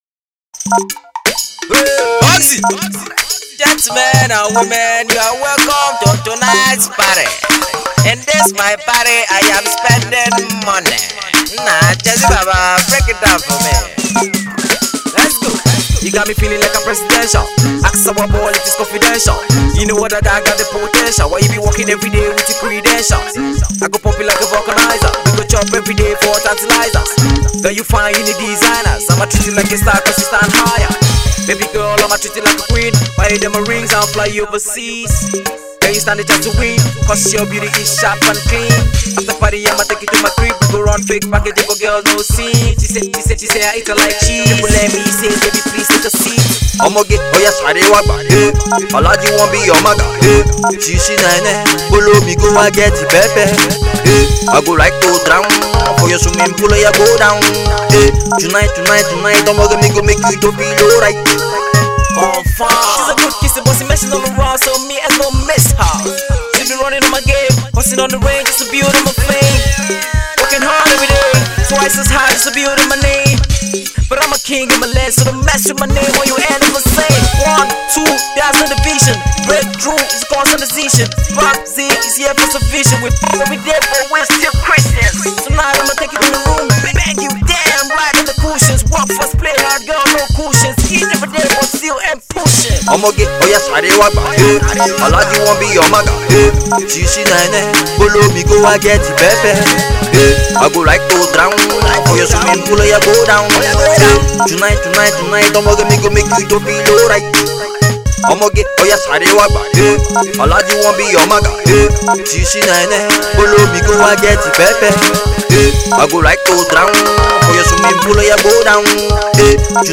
Hip-Hop/Afro Hip-Hop